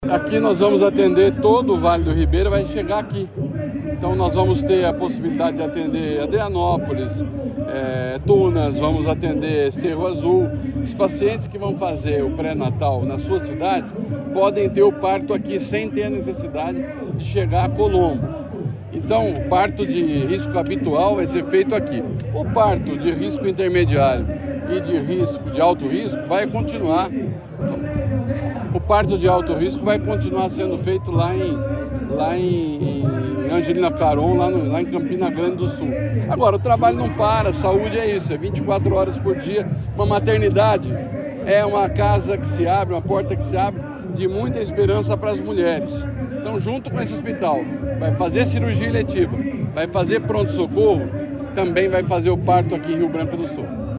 Sonora do secretário da Saúde, Beto Preto, sobre o novo Hospital e Maternidade Municipal de Rio Branco do Sul